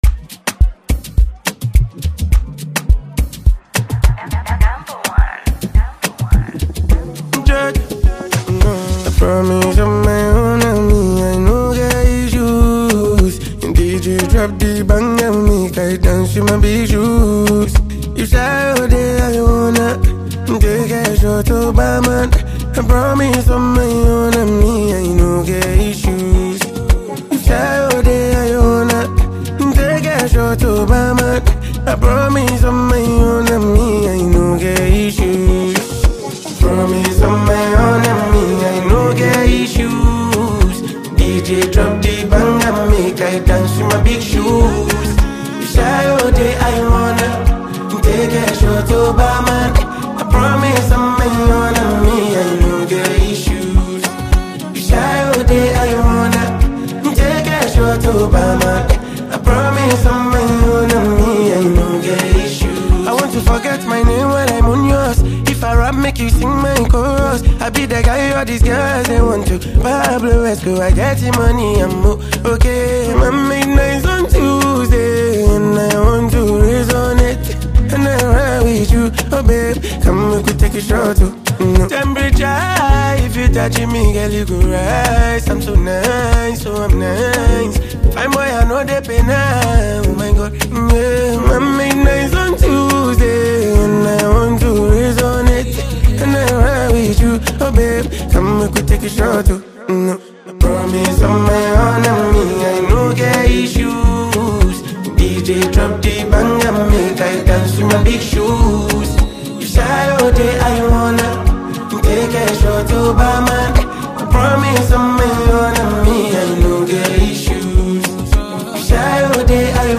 signature vocal flair
smooth Afrobeat rhythms with heartfelt emotions